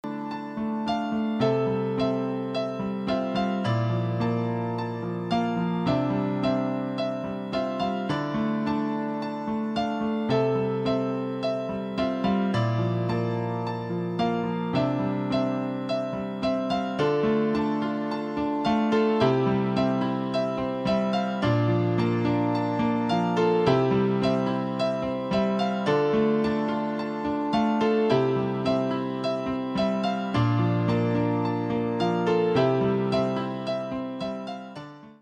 Пианино